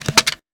weapon_foley_pickup_17.wav